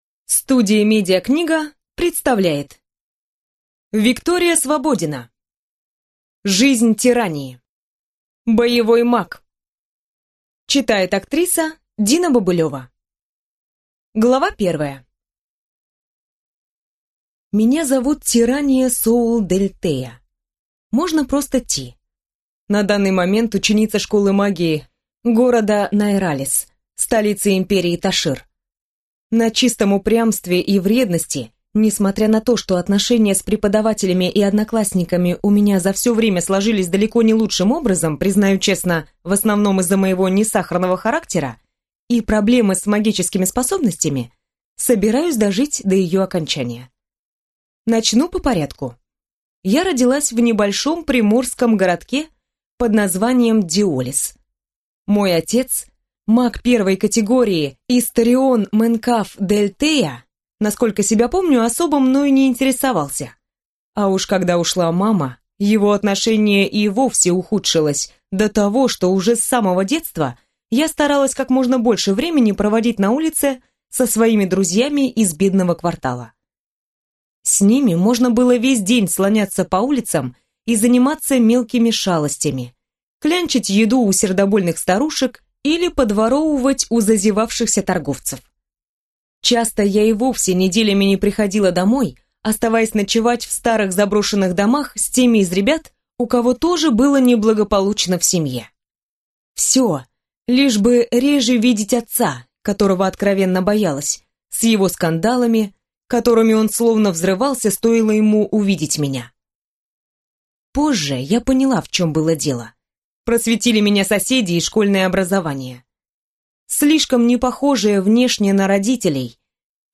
Аудиокнига Жизнь Тиррании. Боевой маг | Библиотека аудиокниг